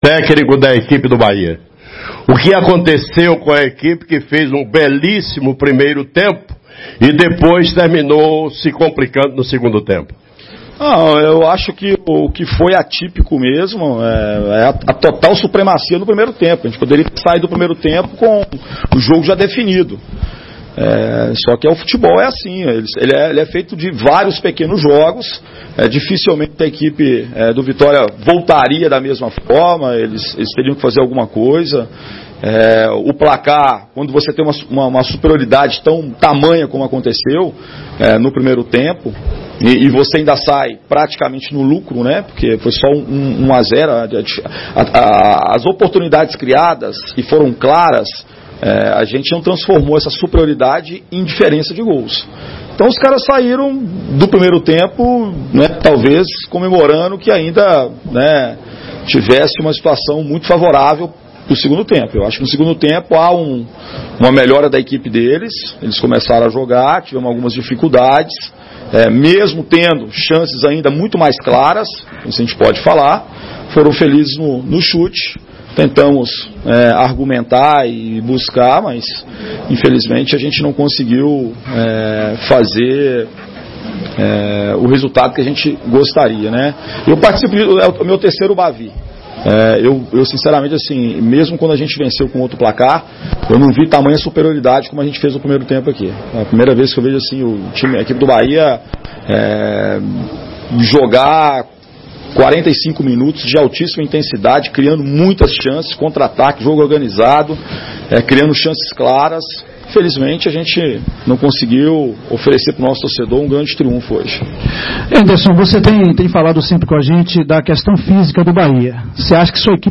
Após o jogo, em entrevista coletiva, o técnico Enderson Moreira foi perguntado sobre as vaias, não só para Rogério, como também o meia-atacante Élber que entrou desligado no jogo e também não agradou ao torcedor.